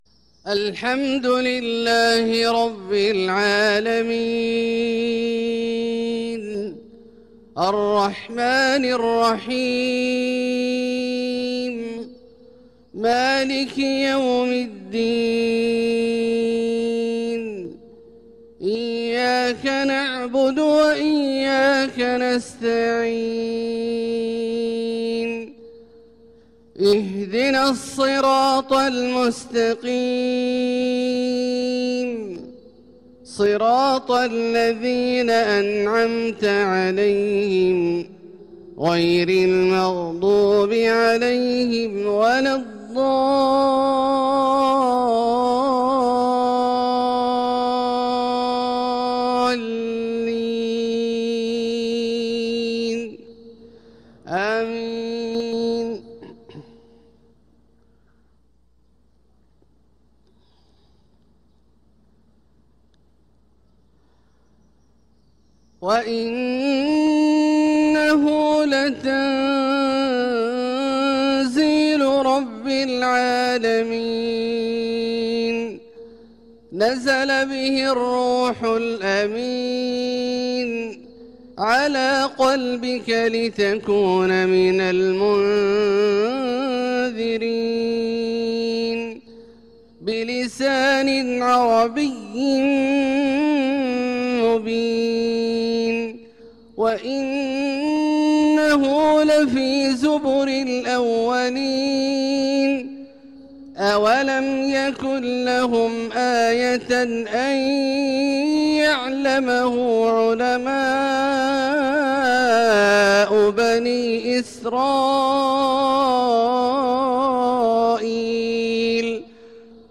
صلاة الفجر للقارئ عبدالله الجهني 13 ذو القعدة 1445 هـ
تِلَاوَات الْحَرَمَيْن .